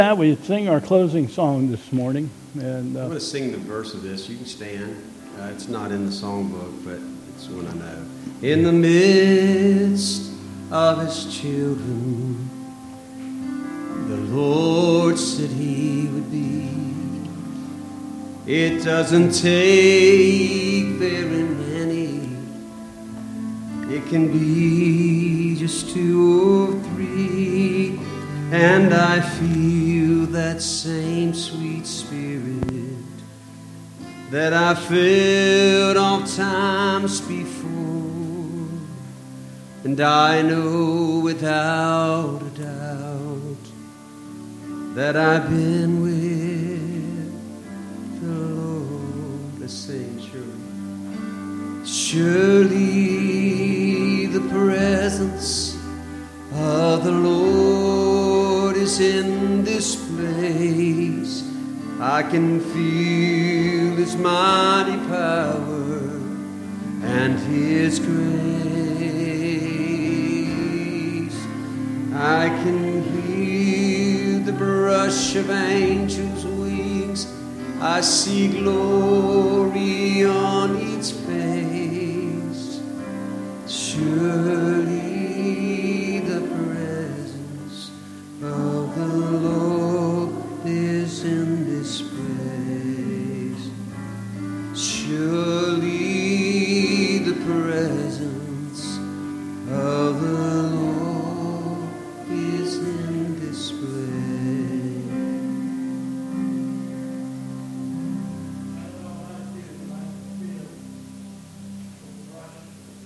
2021 Bethel Covid Time Service
Holy Communion